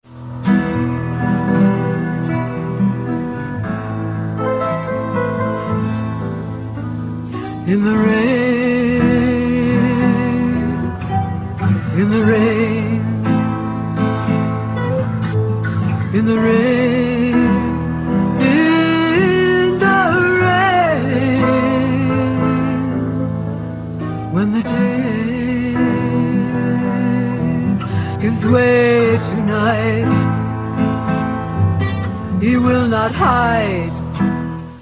Real Audio/mono